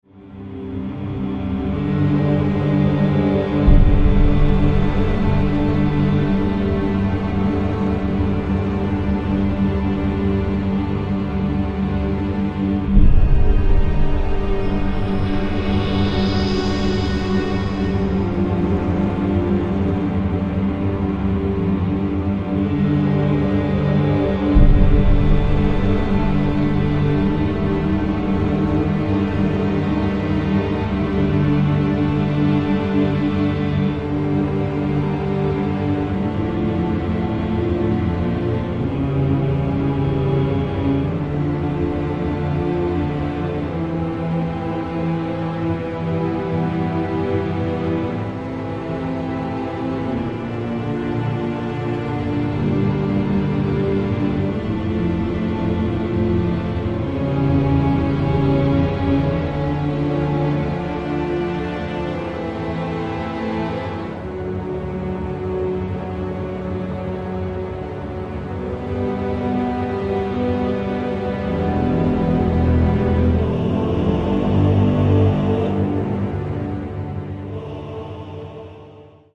2 ambient tracks and 11 orchestral styled tracks.